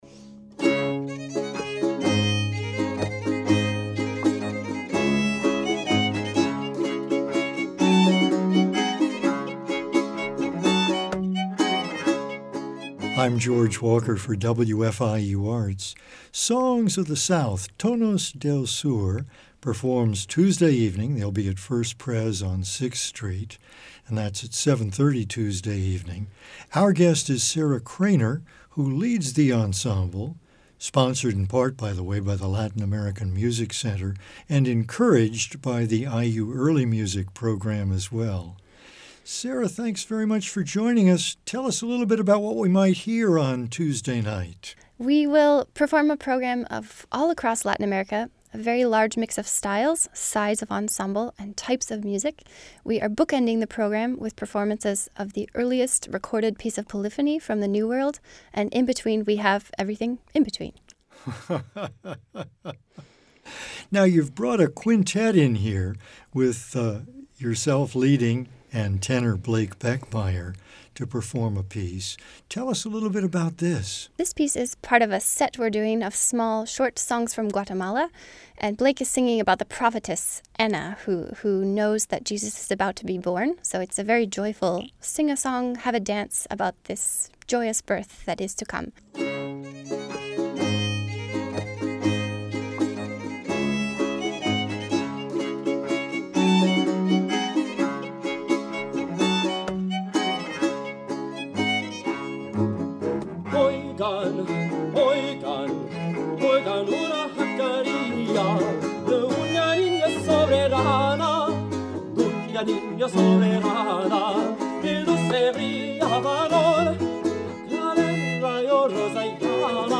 baroque and classical music from Latin America on period instruments
Cellist
guitarist
violinists
tenor